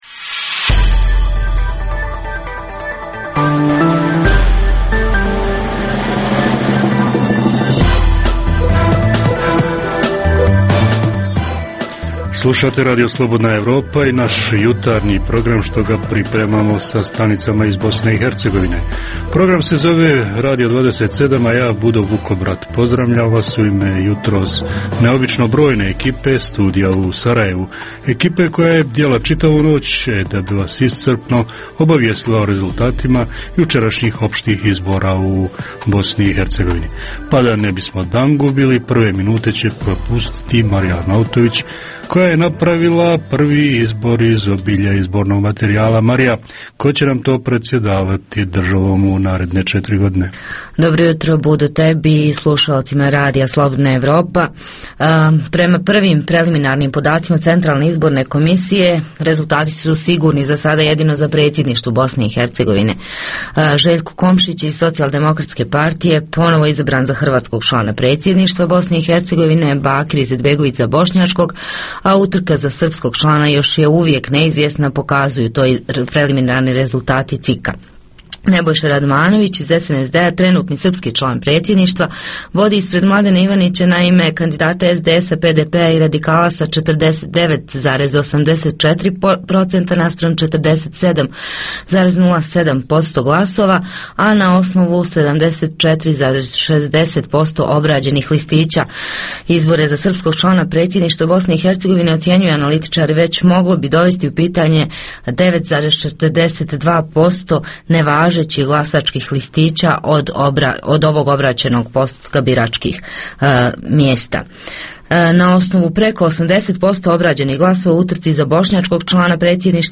Prvog postizbornog dana jutarnji program Radija 27 je posvećen rezultatima glasanja. Poslušajte šta javljaju naši dopisnici iz raznih krajeva BiH.
- Redovni sadržaji jutarnjeg programa za BiH su i vijesti i muzika.